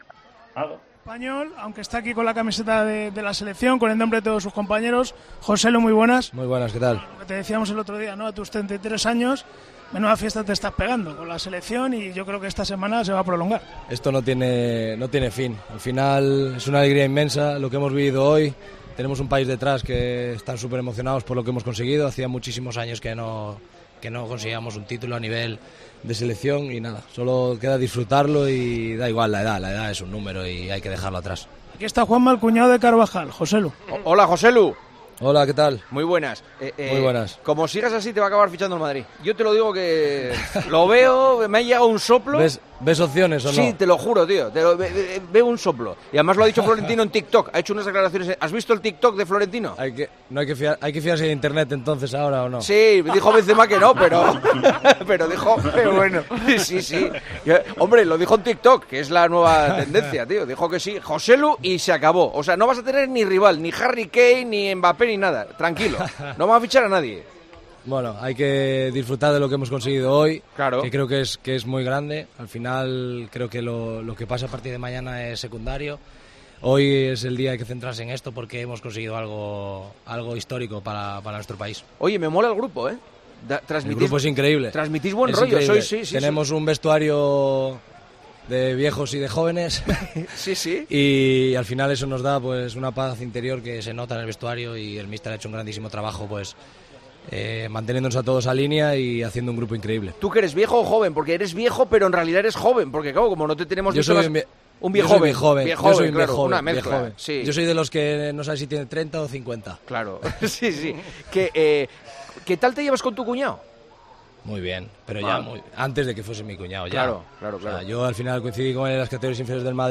Hablamos con el delantero de la selección española tras ganar la Liga de las Naciones sobre su futuro fichaje por el Real Madrid.